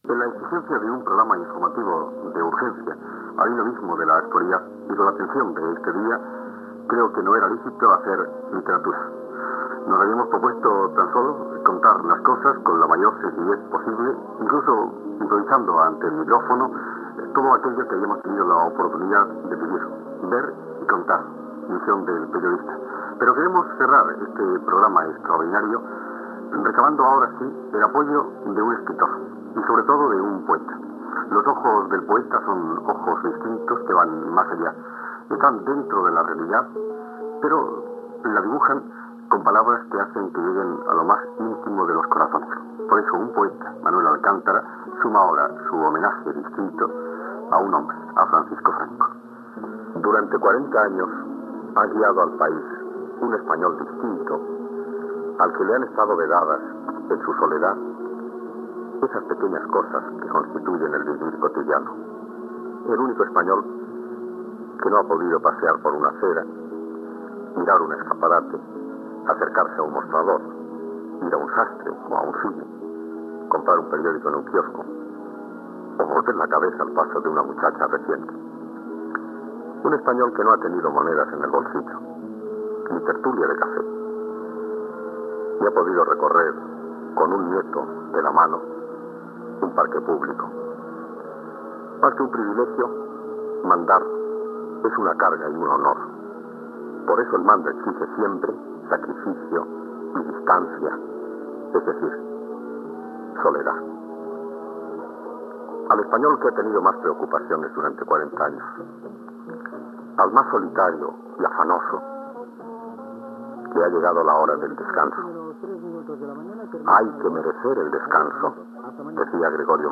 Informatiu especial de les 22 hores.
Informatiu